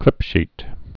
(klĭpshēt)